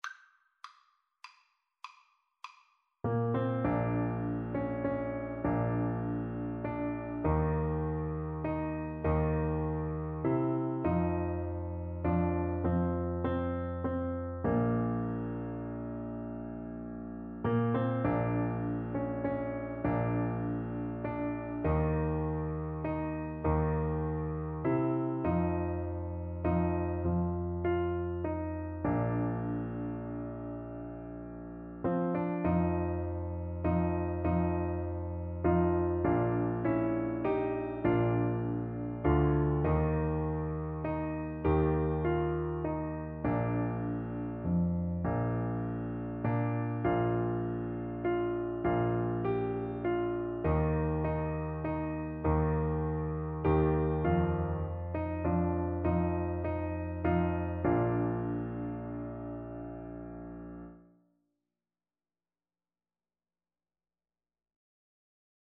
Free Sheet music for Piano Four Hands (Piano Duet)
6/8 (View more 6/8 Music)
Classical (View more Classical Piano Duet Music)